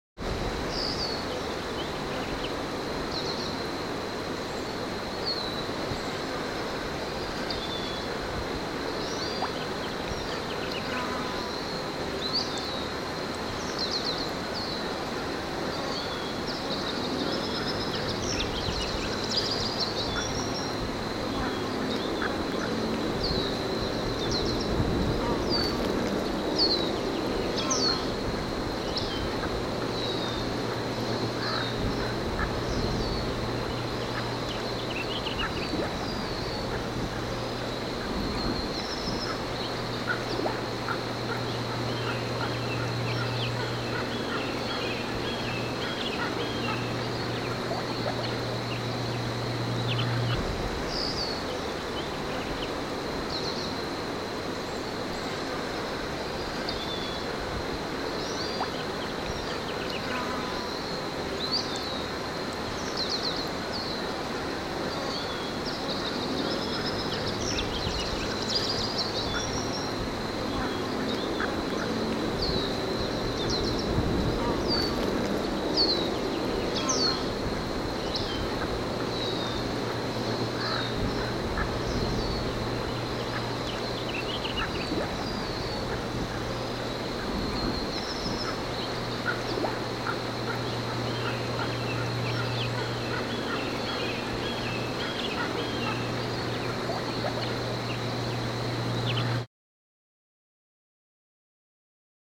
دانلود صدای پرنده 3 از ساعد نیوز با لینک مستقیم و کیفیت بالا
جلوه های صوتی